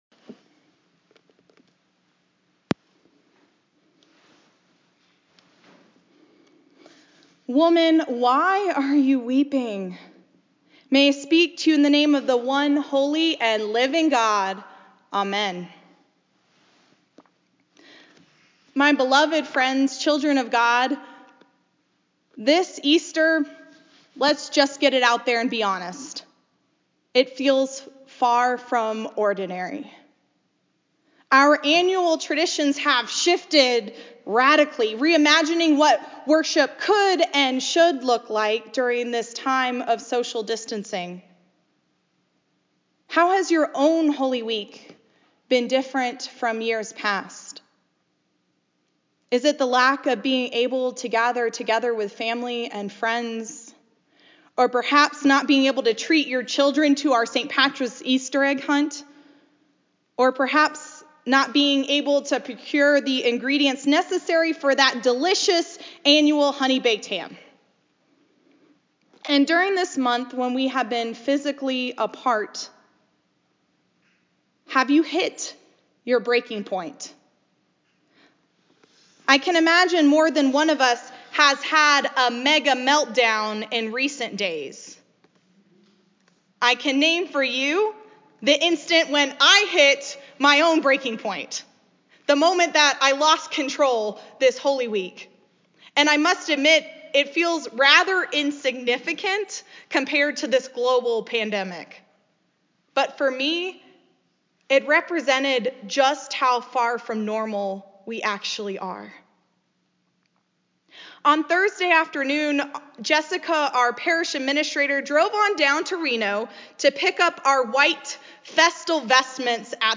A Sermon for Easter Sunday, April 12, 2020 John 20:1-18, NRSV